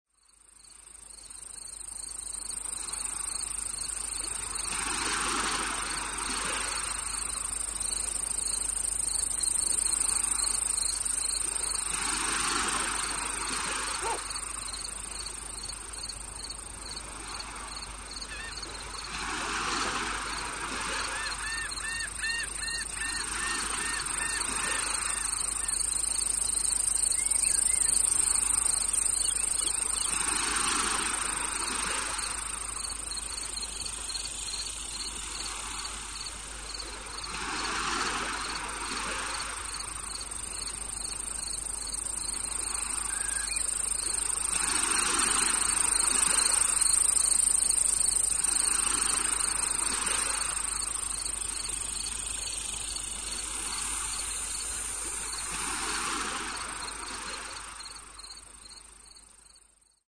Category: Animals/Nature   Right: Personal